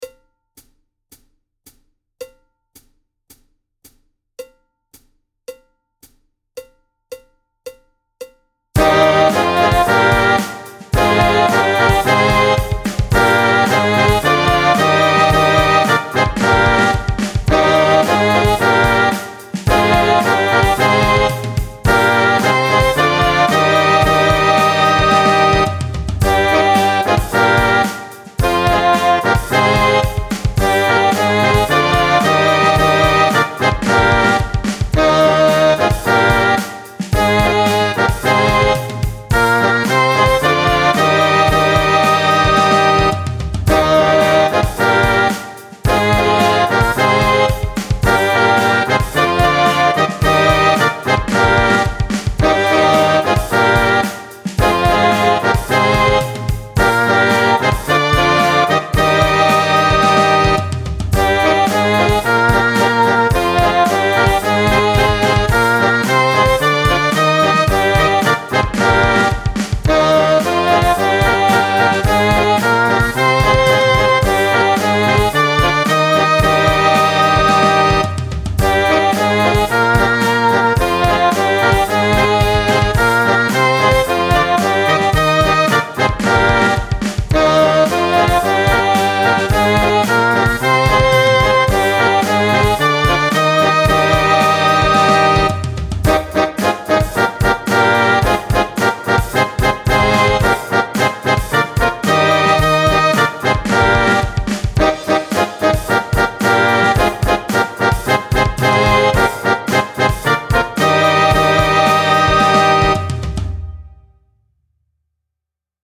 – die Stimmung ist 440Hertz
– der Einzähler des Playbacks beginnt 4 Takte vor dem Song
– das Playback ist aktuell noch eine Midi-Version
Zweistimmige Übung - Tempo 110
Nr. 14 bis 19 | Tutti